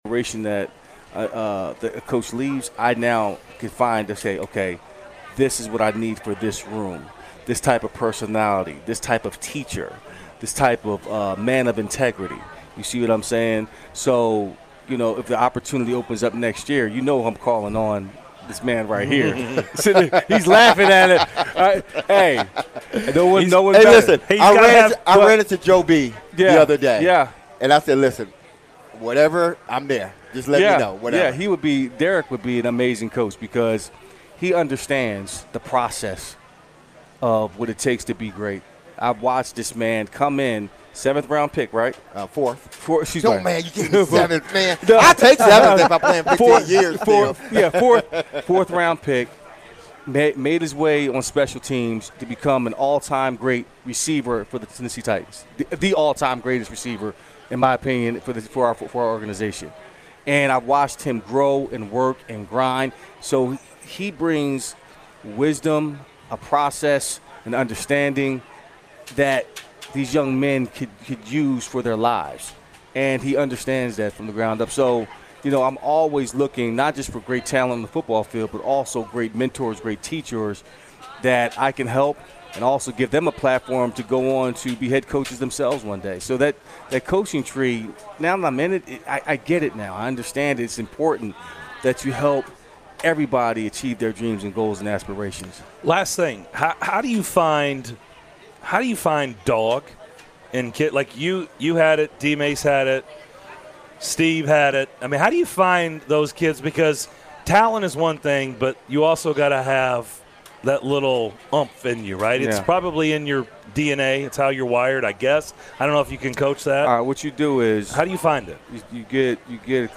Tennessee State University Head Coach Eddie George joins the guys for 615 Day at Ford Ice Center in Antioch to talk about TSU Tigers and the state of HBCU football.